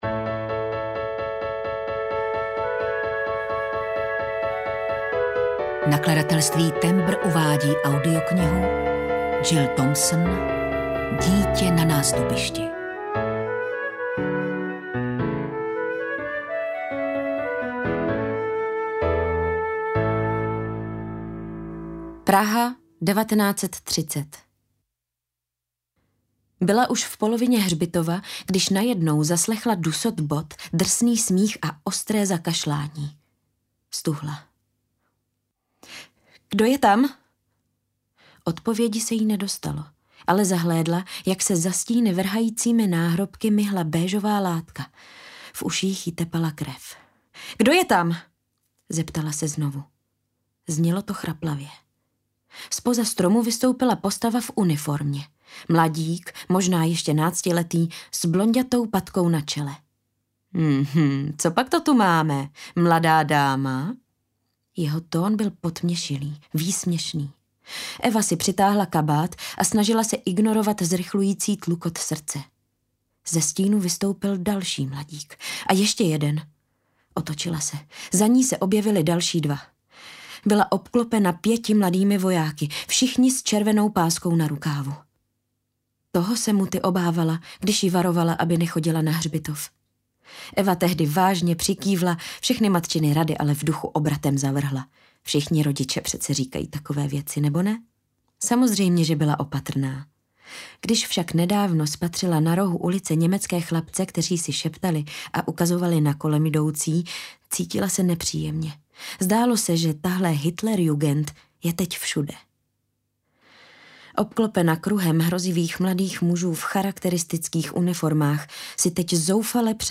Dítě na nástupišti audiokniha
Ukázka z knihy